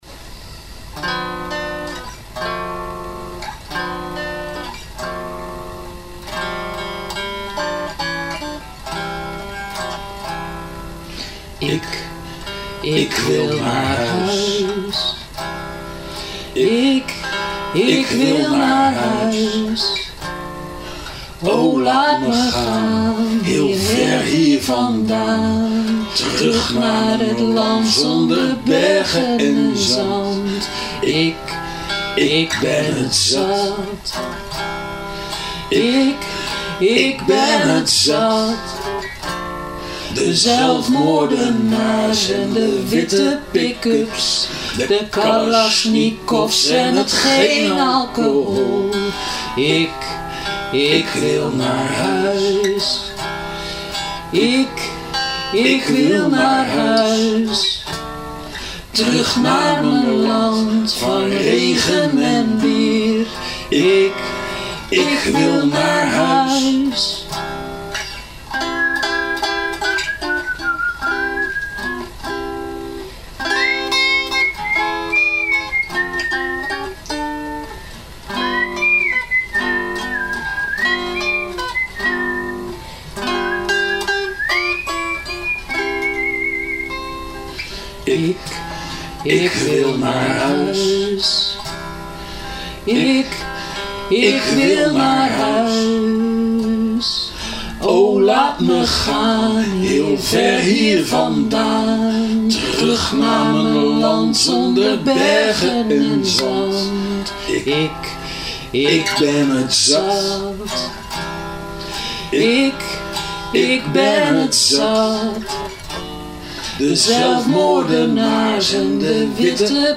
Gezellige samenzang! - Ik wil naar huis (Eerste Wereldoorlog-gedicht in nieuw jasje.